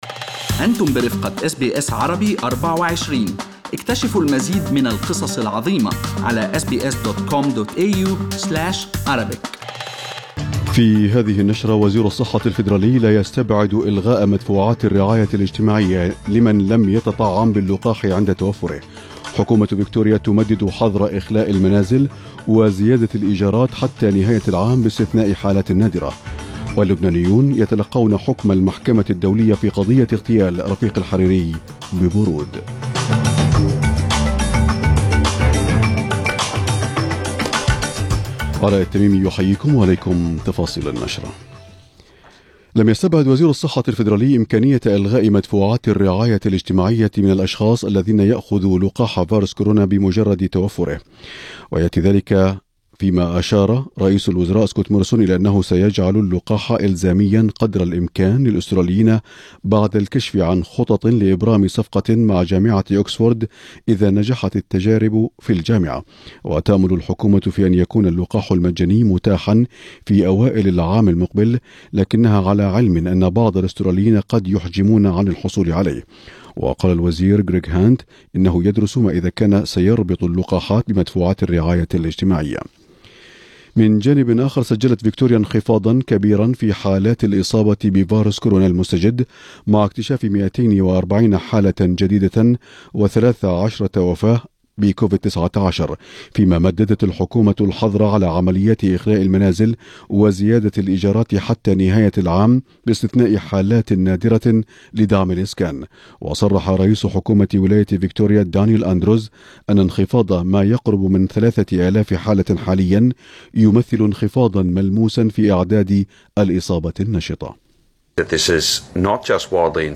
نشرة أخبار المساء 20/8/2020